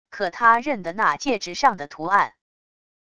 可他认得那戒指上的图案wav音频生成系统WAV Audio Player